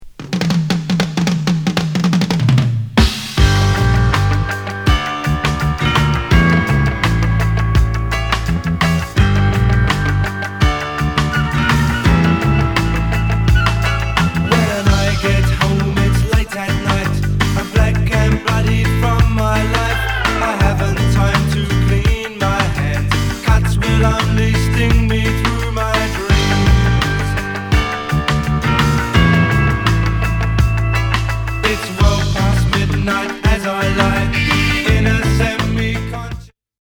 81年リリース、鐘の音から全く不吉な、イギリスの